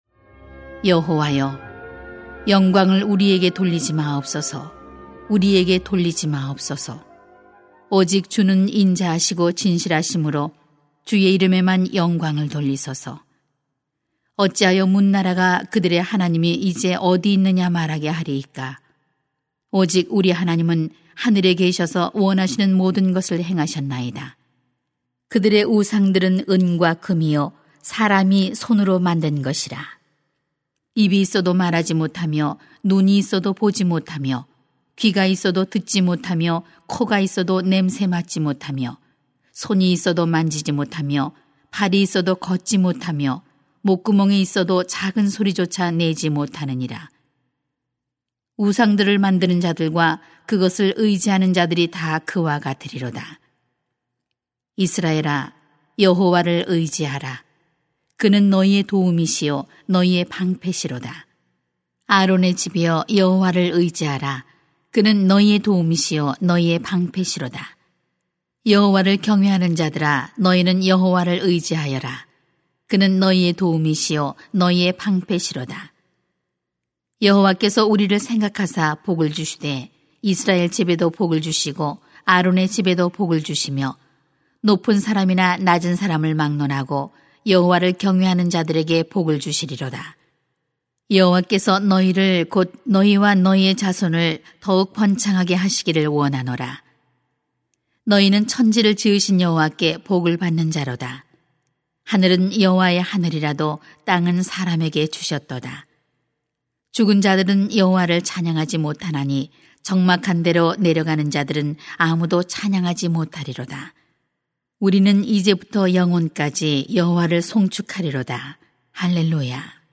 [시 115:1-18] 여호와를 의지하는 사람이 복을 누립니다 > 새벽기도회 | 전주제자교회